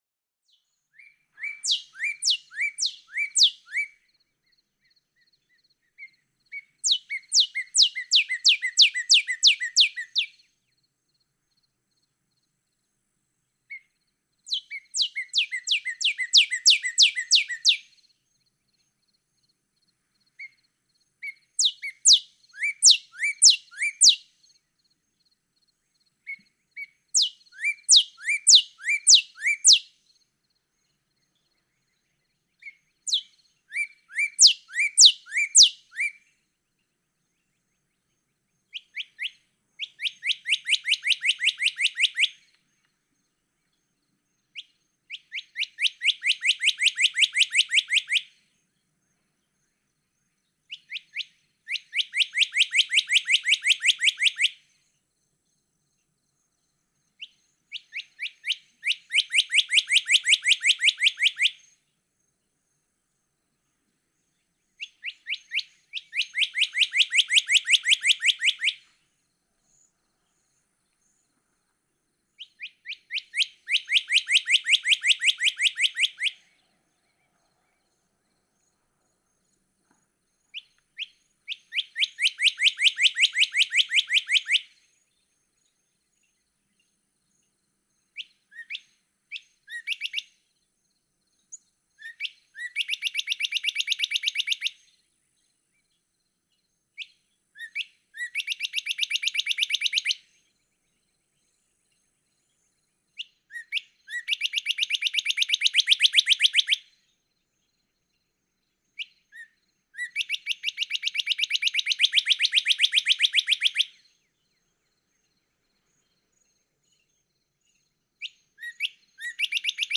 Northern cardinal
And more cardinal songs, accompanied by waves beside the Gulf of Mexico.
Naples, Florida.